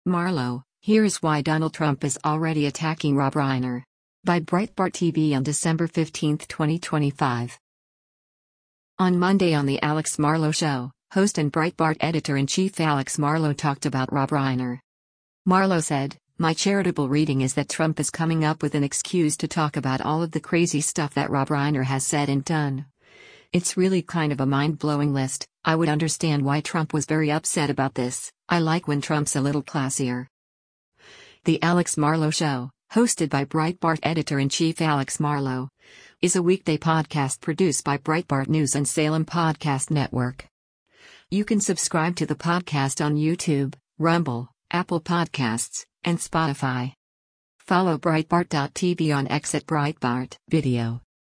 On Monday on “The Alex Marlow Show,” host and Breitbart Editor-in-Chief Alex Marlow talked about Rob Reiner.
“The Alex Marlow Show,” hosted by Breitbart Editor-in-Chief Alex Marlow, is a weekday podcast produced by Breitbart News and Salem Podcast Network.